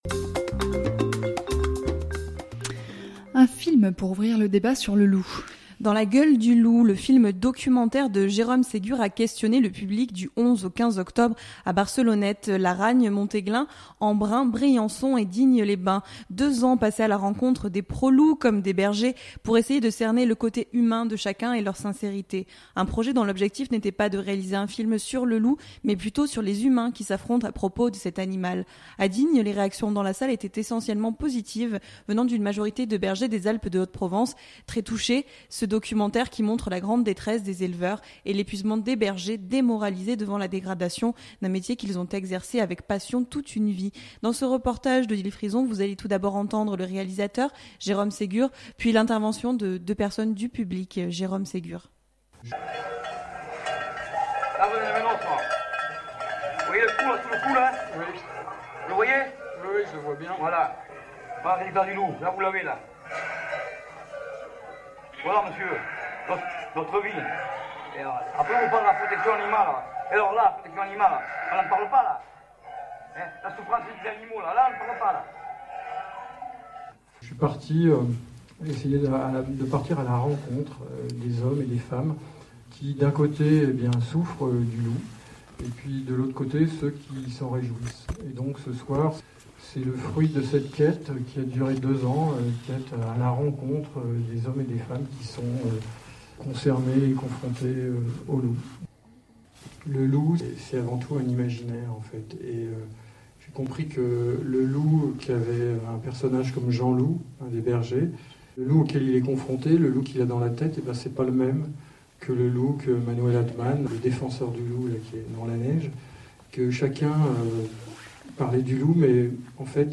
Un projet dont l’objectif n’était pas de réaliser un film sur le loup, mais plutôt sur les humains qui s’affrontent à propos de l’animal. A Digne, les réactions dans la salle étaient essentiellement positives venant d’une majorité de bergers des Alpes de Haute-Provence, très touchés par ce documentaire qui montre la grande détresse des éleveurs et l’épuisement des bergers, démoralisés devant la dégradation d’un métier qu’ils ont exercé avec passion toute une vie.